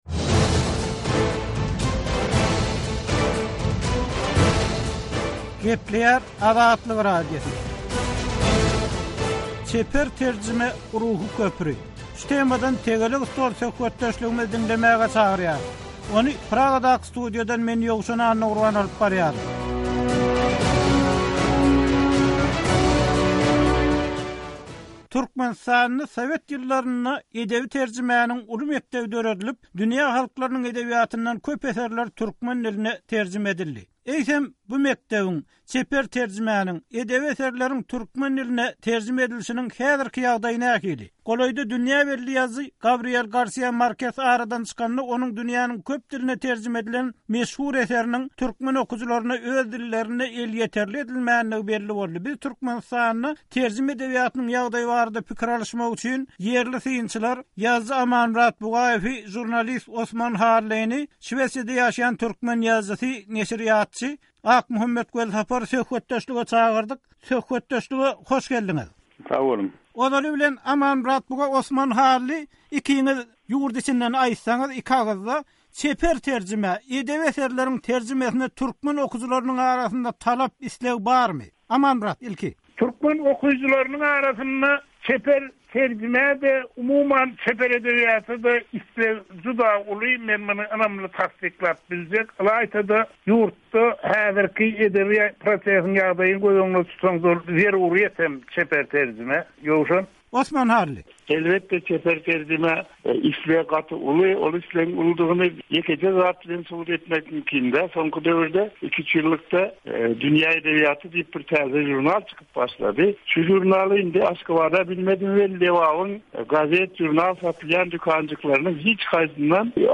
Azatlyk Radiosynyň nobatdaky «Tegelek stol» söhbetdeşligine gatnaşan synçylar türkmen terjimeçilik mekdebiniň weýran edilendigini aýdýarlar.